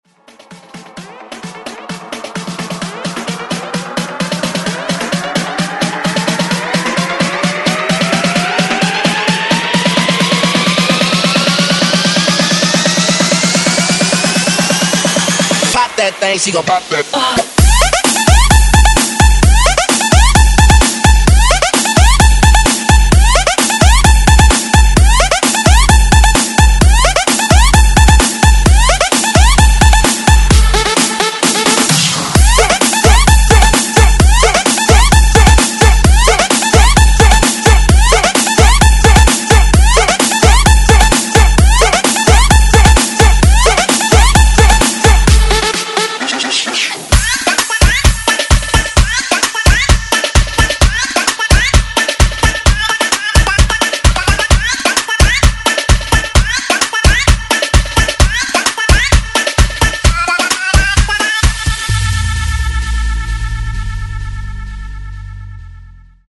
Genre: 90's
Clean BPM: 120 Time